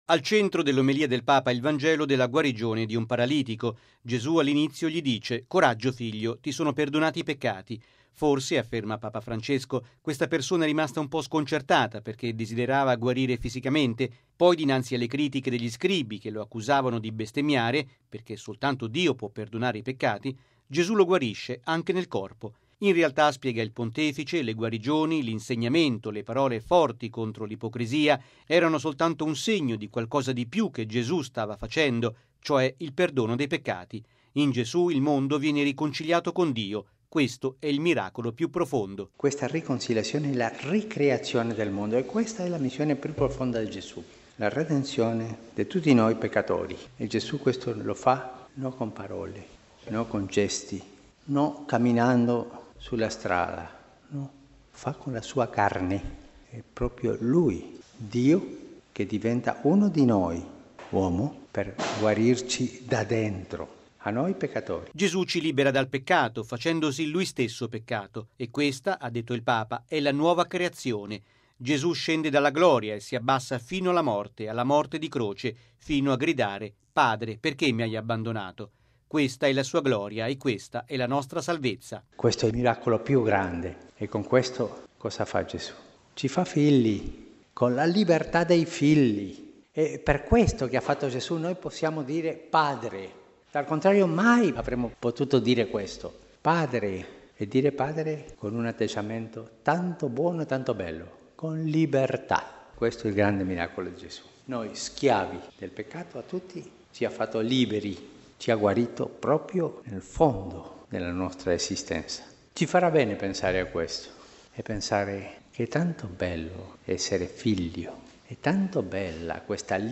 ◊   Noi siamo figli di Dio grazie a Gesù, nessuno ci può rubare questa carta d’identità: è quanto ha affermato stamani Papa Francesco durante la Messa a “Casa Santa Marta”.